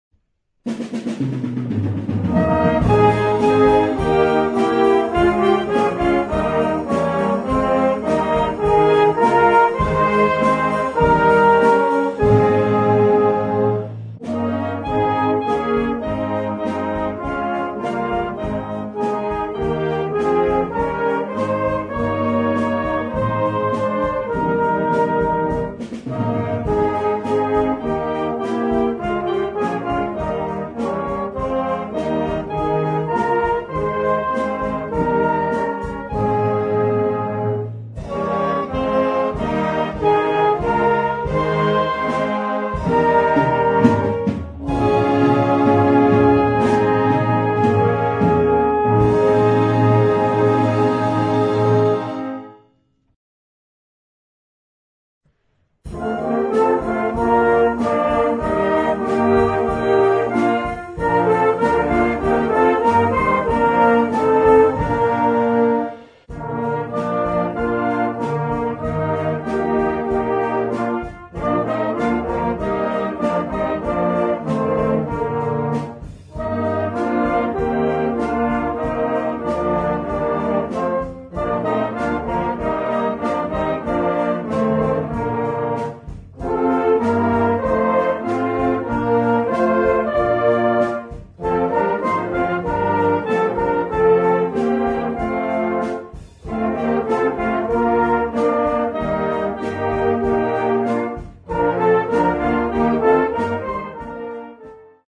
Partitions pour ensemble flexible, 4-voix + percussion.